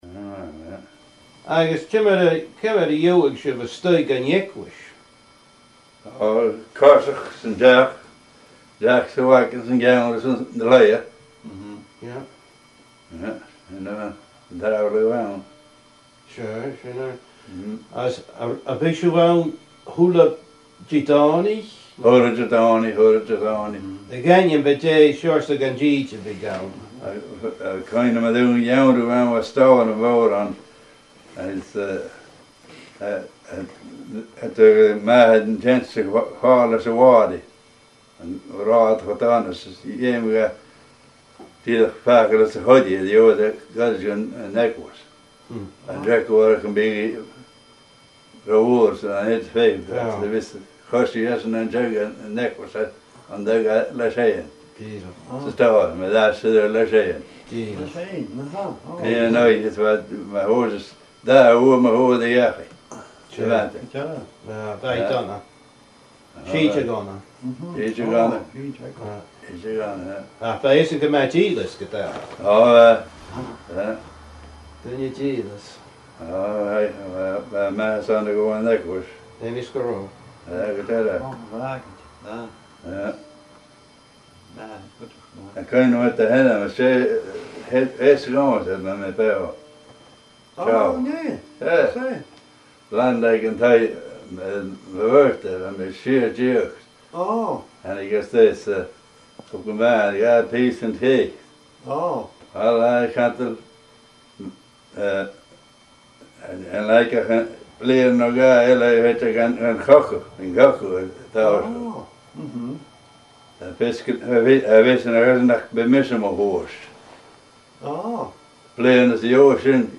An Neach-agallaimh